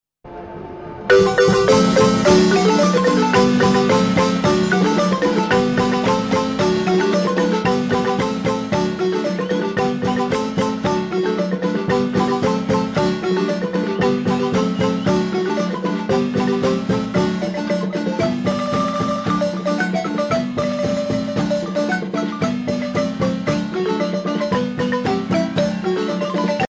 danse : bamba
Pièce musicale éditée